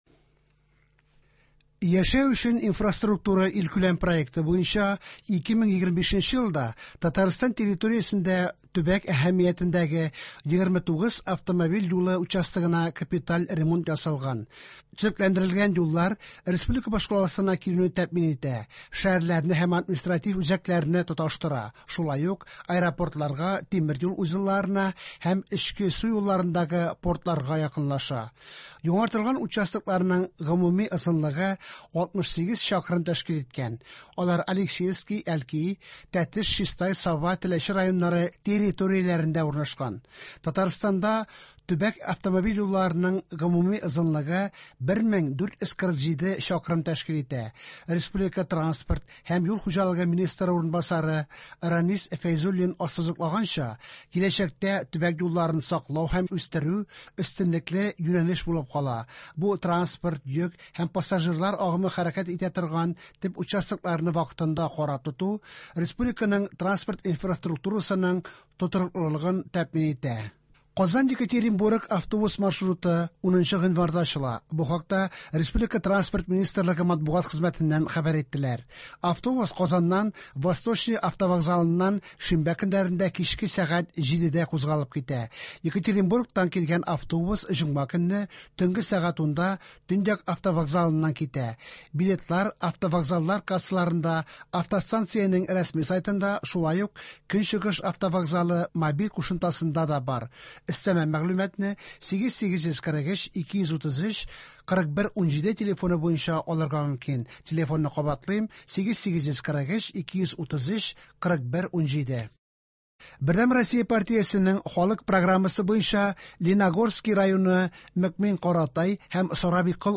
Иртәнге чыгарылыш.